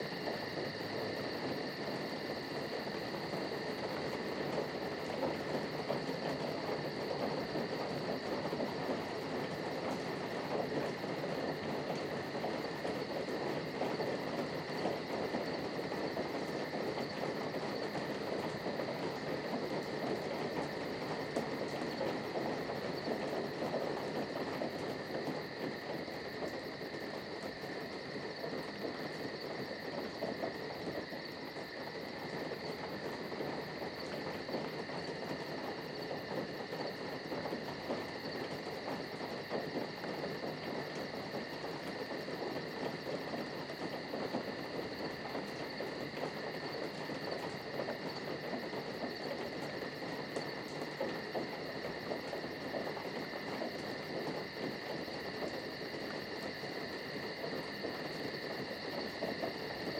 Inside Night Rain.wav